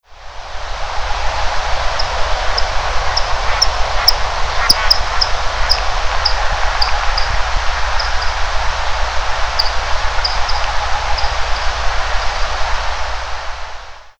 Hydropsalis torquata (brasiliana furcifera) - Dormilón tijereta
dormilontijereta.wav